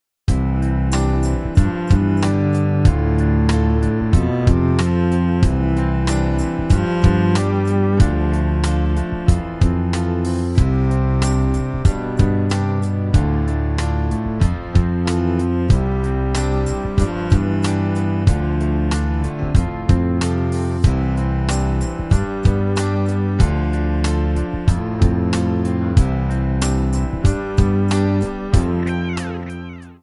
D
Backing track Karaoke
Pop, Musical/Film/TV, 1990s